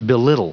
Prononciation du mot belittle en anglais (fichier audio)
Prononciation du mot : belittle